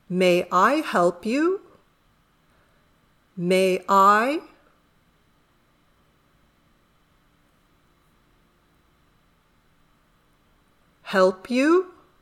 dictado
Primero vas a escuchar la oración a una velocidad normal, y luego lentamente para poder escribirla.